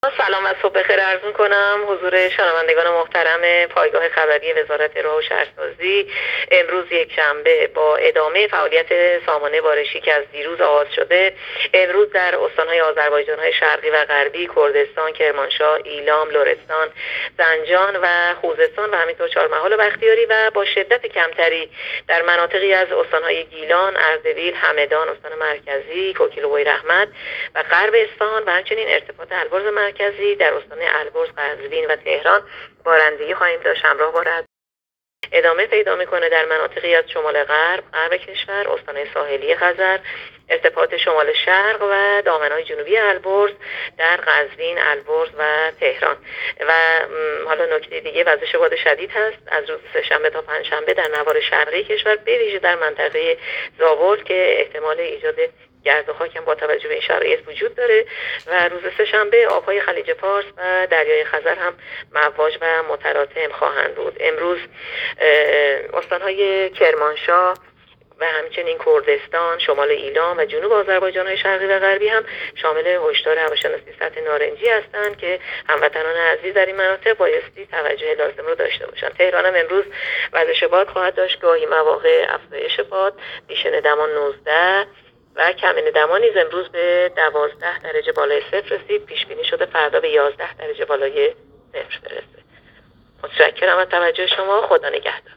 گزارش رادیو اینترنتی پایگاه‌ خبری از آخرین وضعیت آب‌وهوای ۲۵ آبان؛